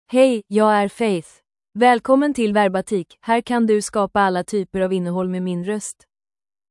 FaithFemale Swedish AI voice
Faith is a female AI voice for Swedish (Sweden).
Voice sample
Listen to Faith's female Swedish voice.
Female
Faith delivers clear pronunciation with authentic Sweden Swedish intonation, making your content sound professionally produced.